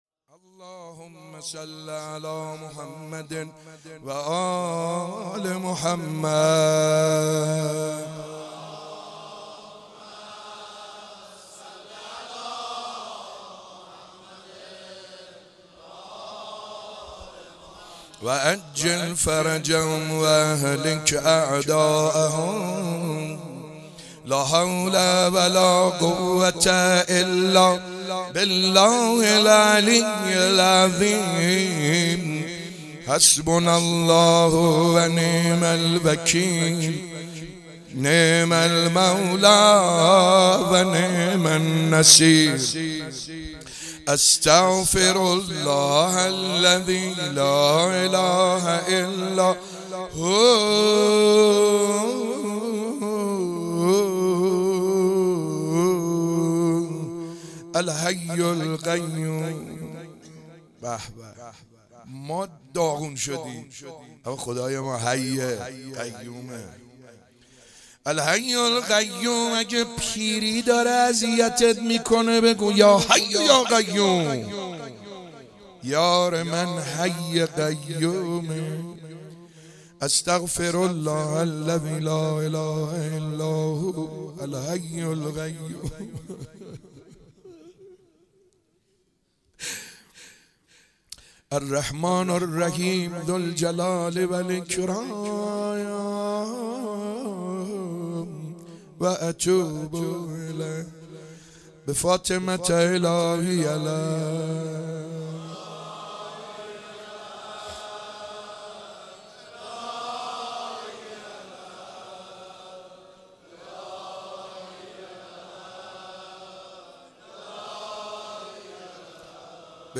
شب 27 ماه مبارک رمضان 96 - روضه - کار علی اصغر من تمام است